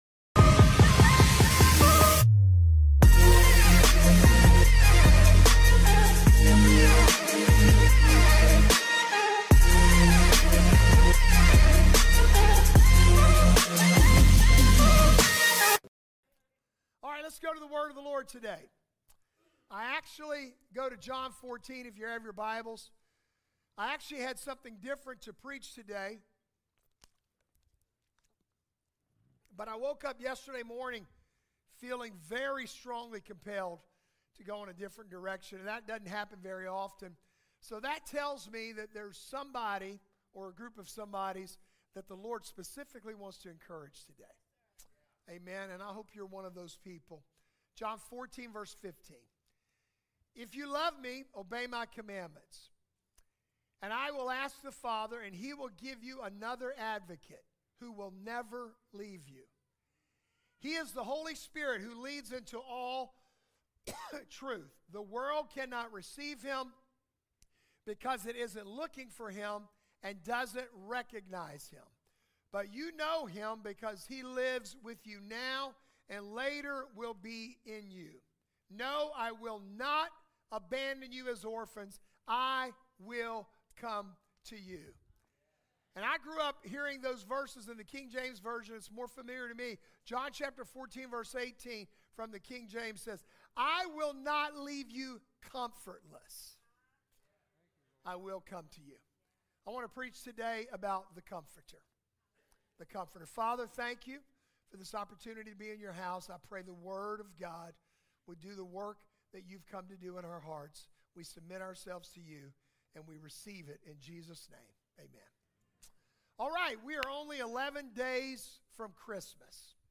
The sermon draws us into John 14, where Jesus promises not to leave us as orphans, but to send the Comforter—the Holy Spirit—who will never abandon us.